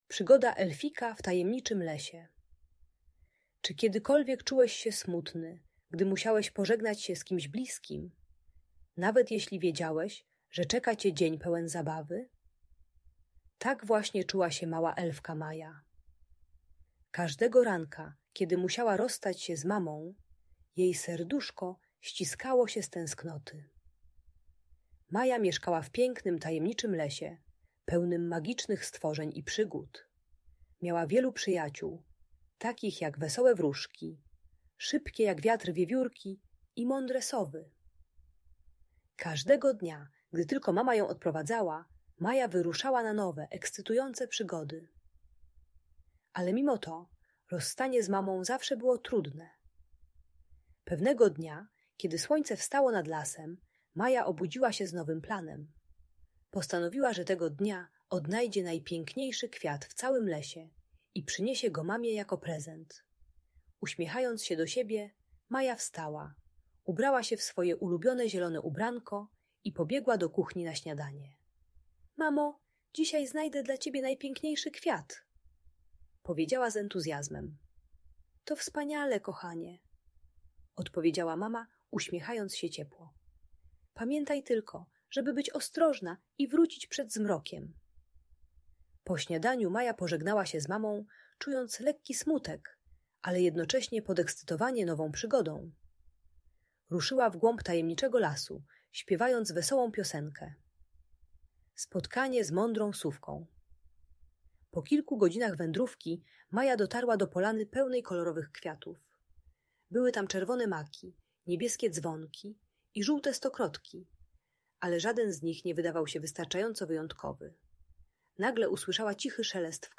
Przygoda Elfika w Tajemniczym Lesie - Magiczna Opowieść - Audiobajka